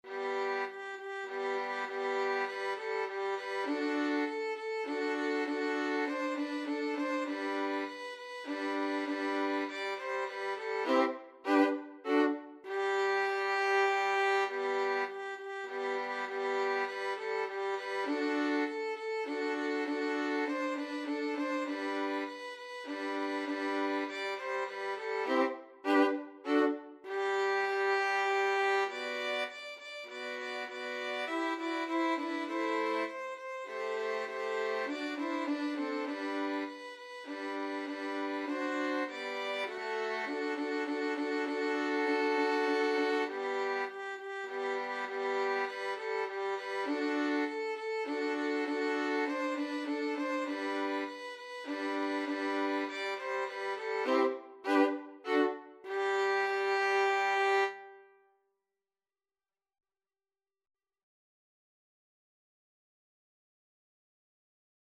Free Sheet music for Violin Trio
Violin 1Violin 2Violin 3
Allegro moderato (View more music marked Allegro)
G major (Sounding Pitch) (View more G major Music for Violin Trio )
3/4 (View more 3/4 Music)
Classical (View more Classical Violin Trio Music)